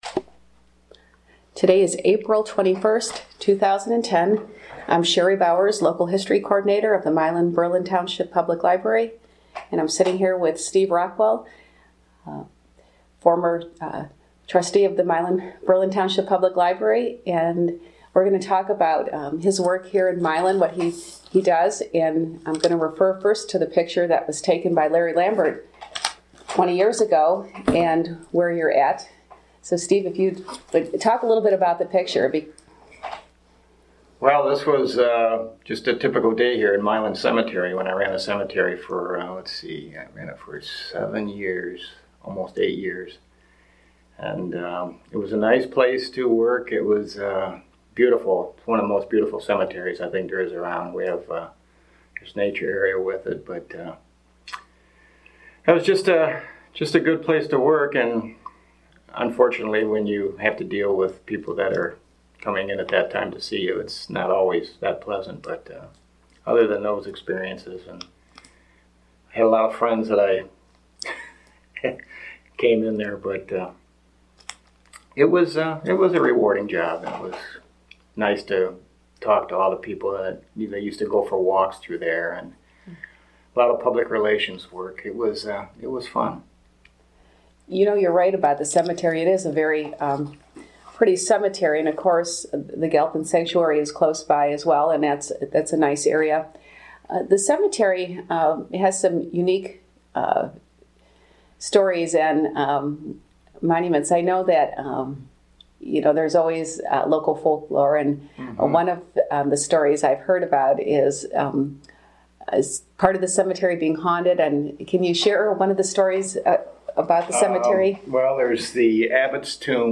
Oral History Project | Steve Rockwell
Milan Mayor Steve Rockwell shares his memories of many events from childhood through his years as a lineman electrician for the village.